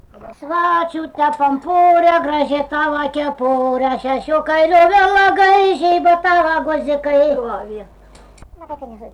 smulkieji žanrai
Erdvinė aprėptis Rageliai
Atlikimo pubūdis vokalinis